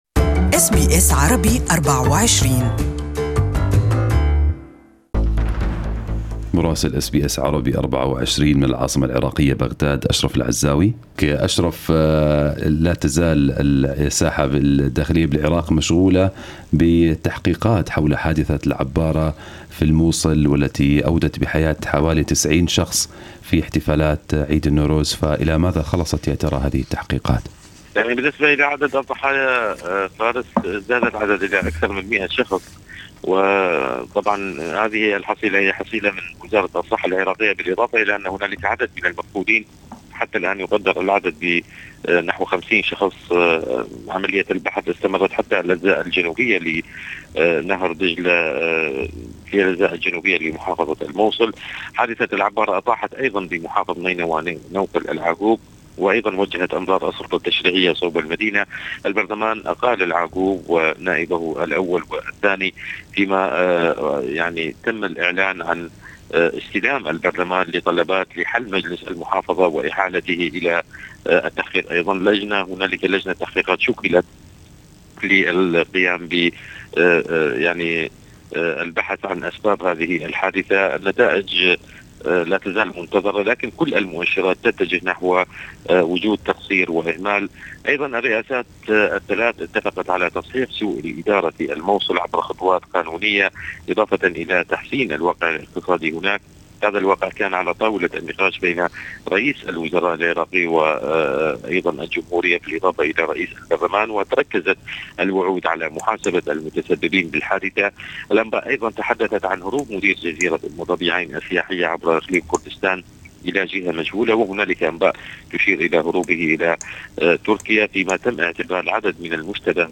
Our Correspondent in Iraq has the details
Listen to the full report from Baghdad in Arabic above